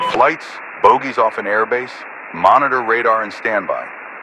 Radio-commandNewEnemyAircraft3.ogg